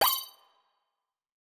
Game Warning Alert Sound Subtle.wav